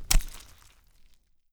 Syringe.wav